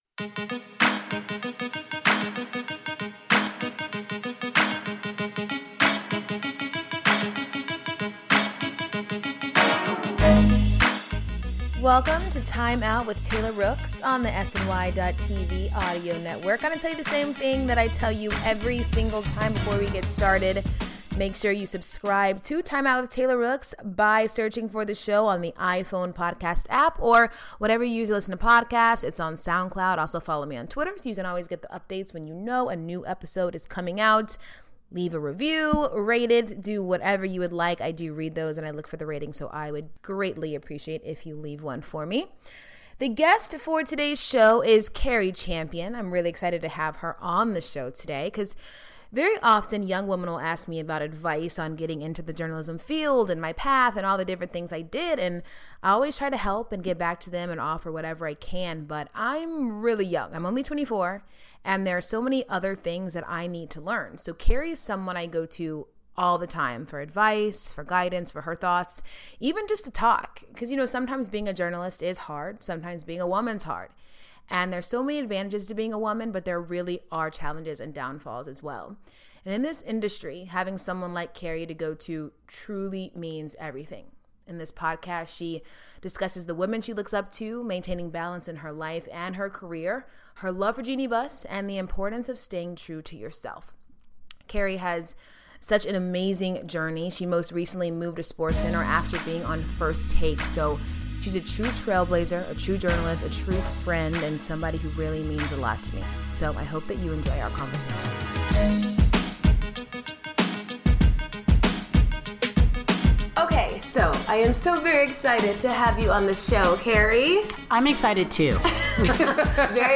In the latest episode of Timeout with Taylor Rooks, ESPN's Cari Champion sits down with Taylor in Los Angeles to discuss the women she looks up to, maintaining balance in her life, and her love of Lakers President Jeanie Buss.